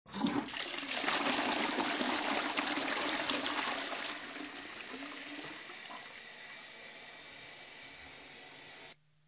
Instrumentals--0 - flushing_toilet